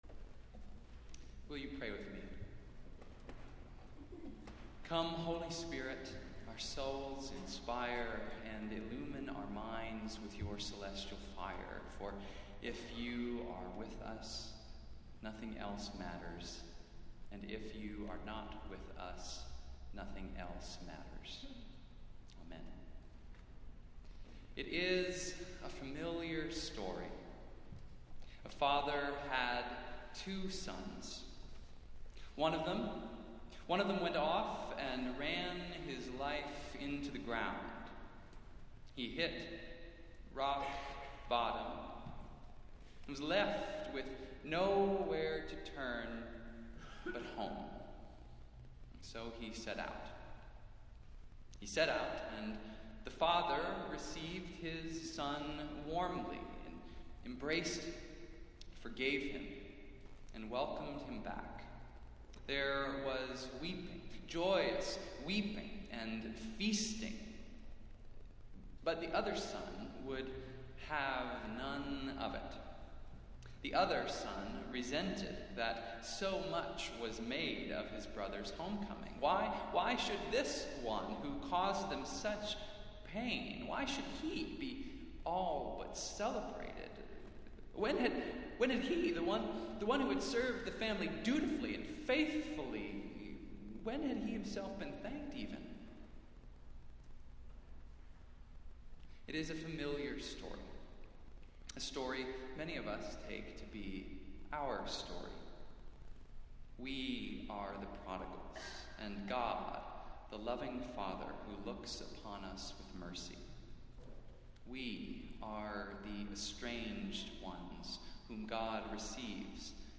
Festival Worship - Fourth Sunday after Pentecost